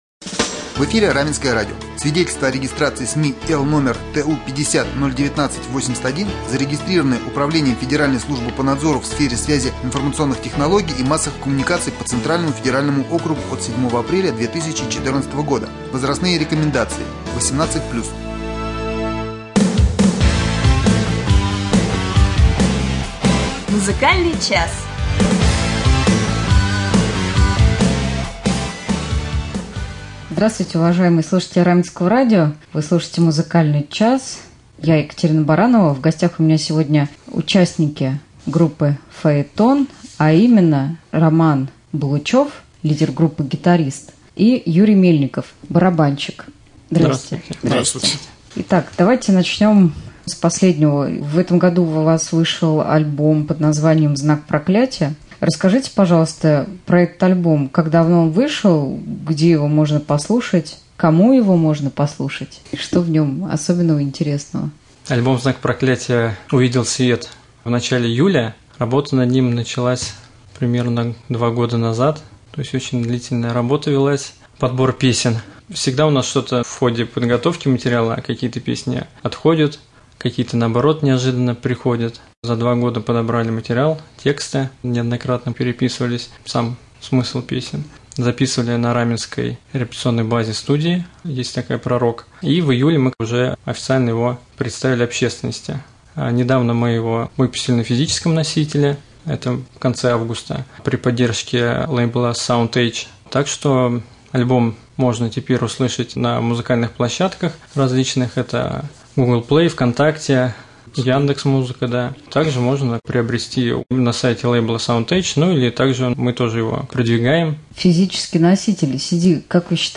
Гости студии — участники группы «Фаэтон»